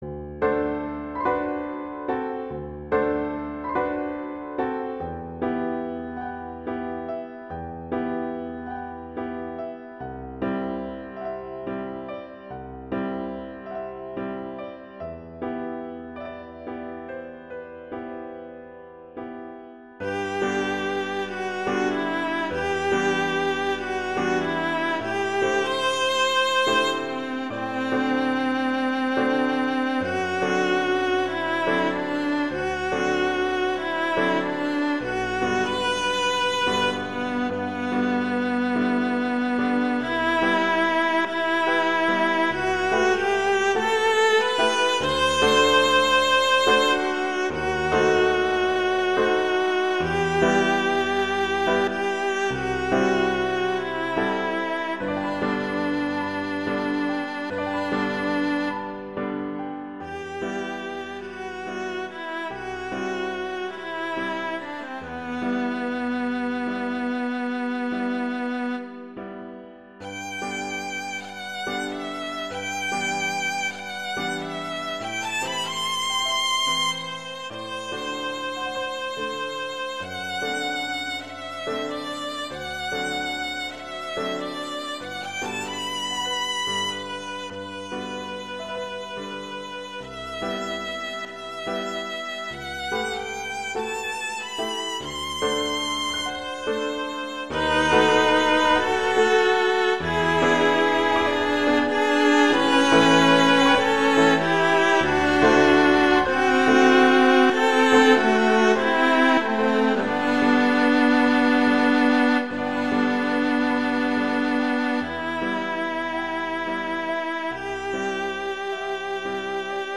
Instrumentation: viola & piano
arrangement for viola and piano
classical
G major
♩. = 48 BPM